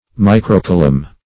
microcoulomb - definition of microcoulomb - synonyms, pronunciation, spelling from Free Dictionary
Search Result for " microcoulomb" : The Collaborative International Dictionary of English v.0.48: Microcoulomb \Mi`cro*cou`lomb"\, n. [Micro- + coulomb.]
microcoulomb.mp3